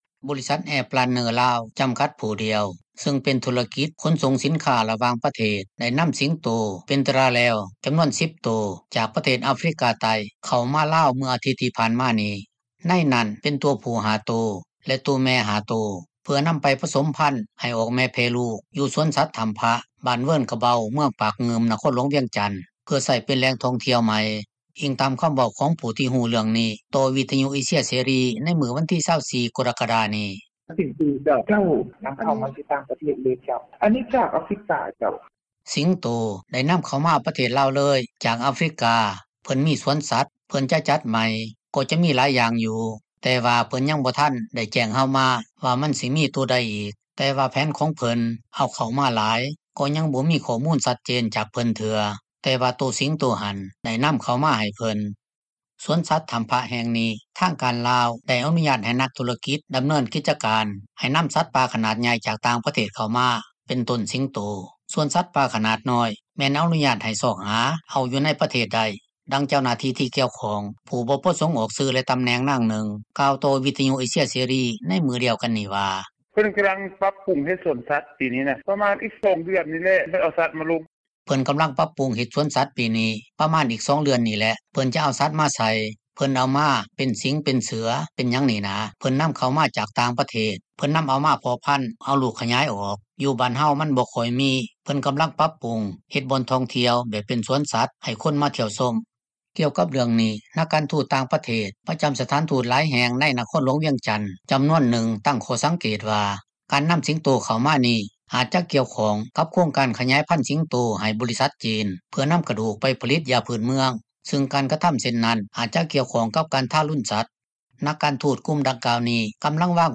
ດັ່ງເຈົ້າໜ້າທີ່ ທີ່ກ່ຽວຂ້ອງ ຜູ້ບໍ່ປະສົງອອກຊື່ ແລະຕໍາແໜ່ງນາງນຶ່ງ ກ່າວຕໍ່ວິທຍຸ ເອເຊັຽ ເສຣີໃນມື້ດຽວກັນນີ້ວ່າ:
ດັ່ງຜູ້ນໍາທ່ຽວຢູ່ນະຄອນ ຫລວງພຣະບາງກ່າວວ່າ: